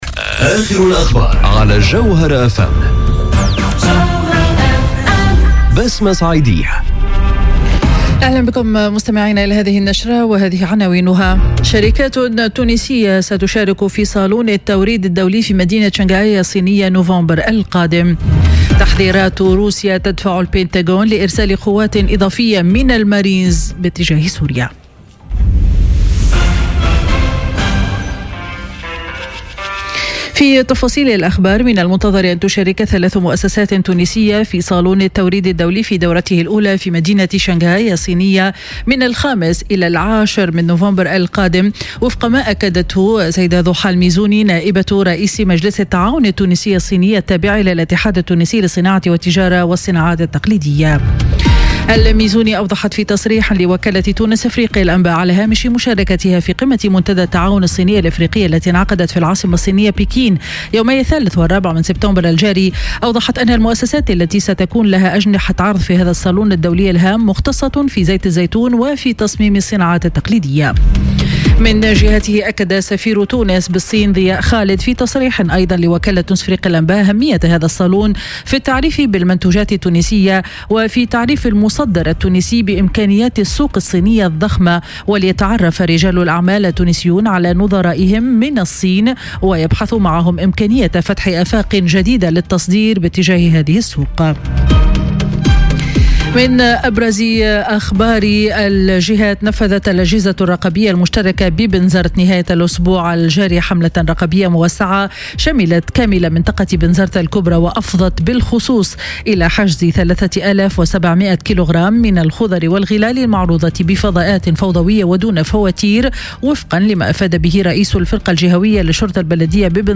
نشرة أخبار منتصف النهار ليوم الأحد 9 سبتمبر 2018